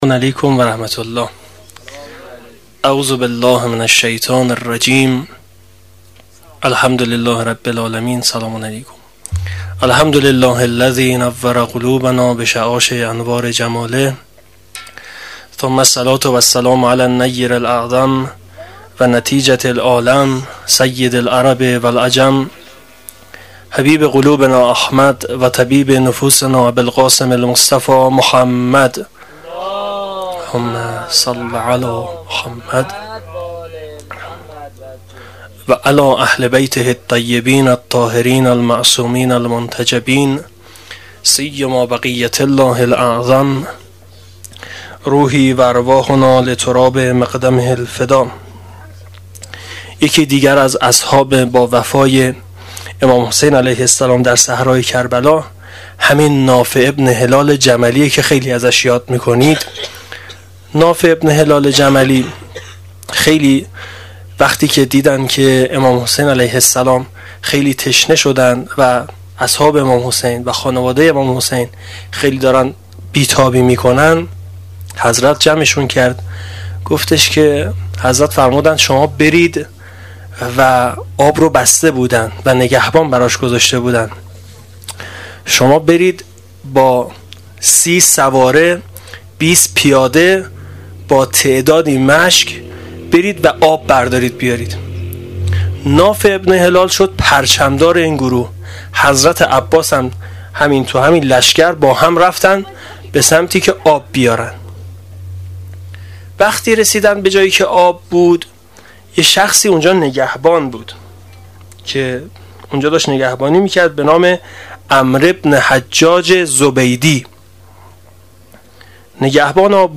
سخنرانی شب پنجم محرم الحرام 1396